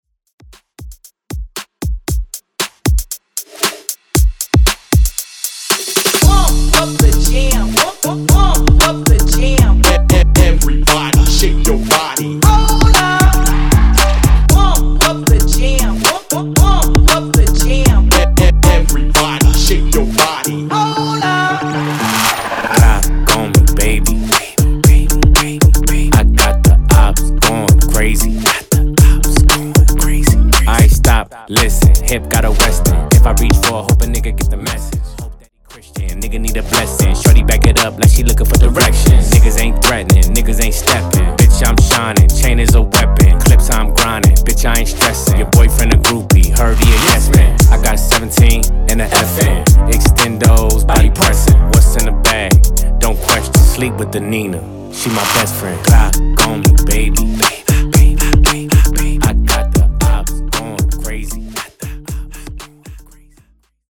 Hip-Hop, R&B